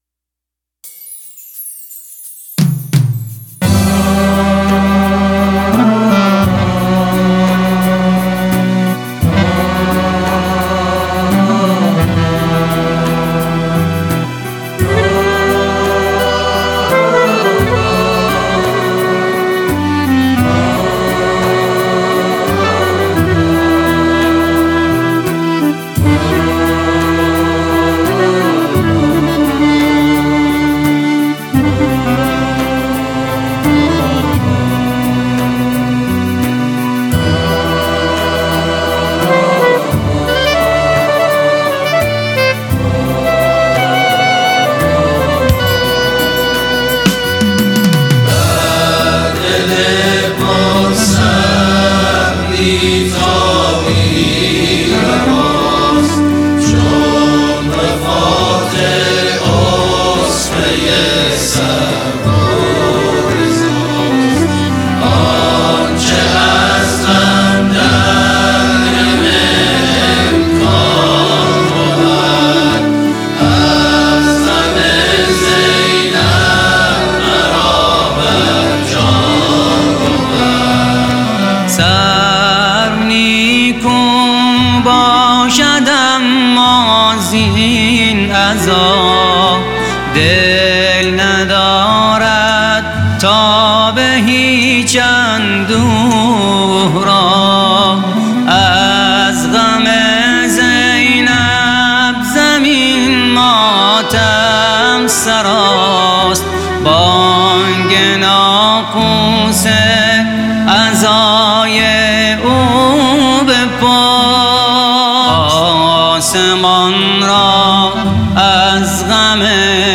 » که توسط گروه موسیقی آیینی آهنگسازی و اجرا شده است را با محور تعزیت و مرثیه پیام‌آور دشت کربلا و راوی نهضت و مکتب حسینی به خوانندگان و همراهان خبرگزاری بین‌المللی قرآن
قطعه موسیقایی و آیینی